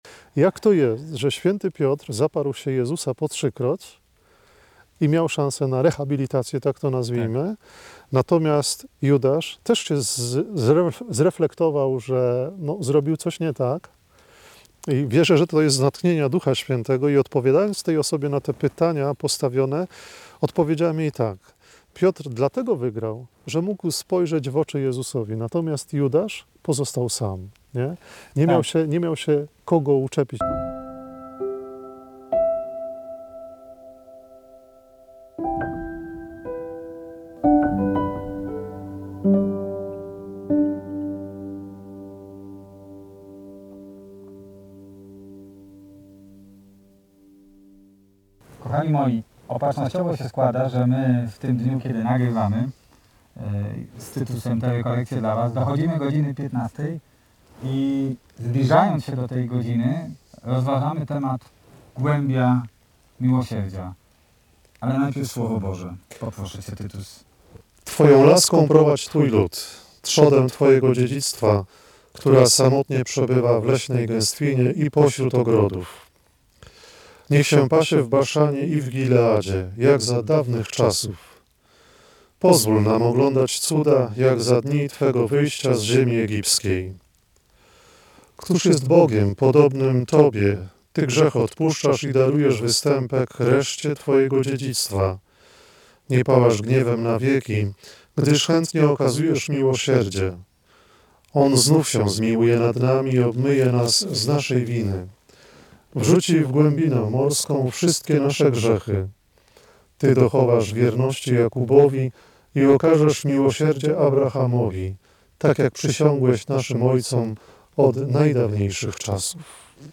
Odcinek 3 rekolekcji.